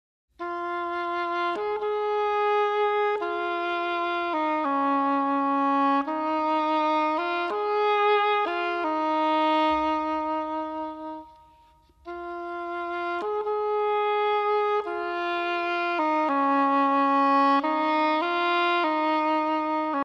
Solo na rożek angielski